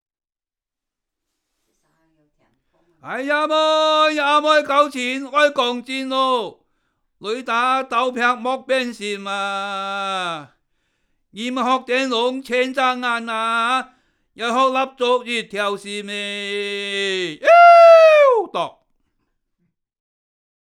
繼3年前〈傳統圍頭．客家歌謠與昔日鄉村生活誌〉的延續，將推出新的客家及圍頭傳統歌謠的光碟。